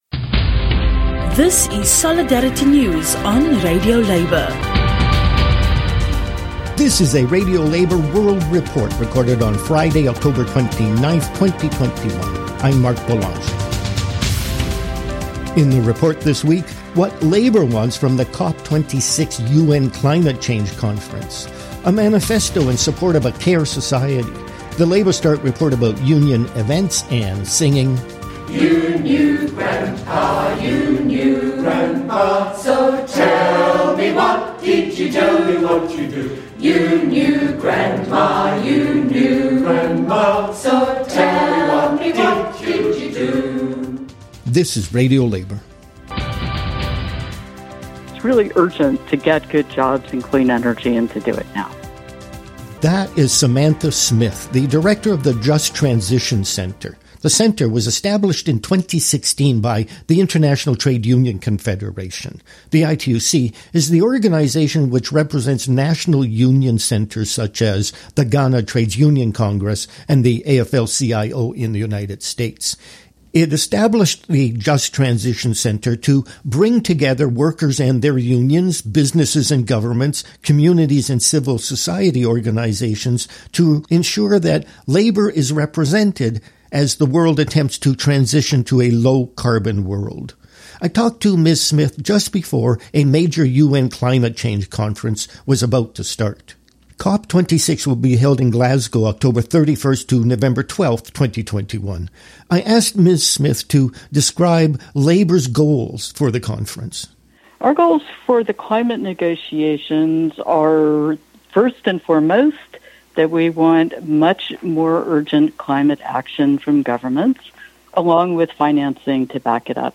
International Labour News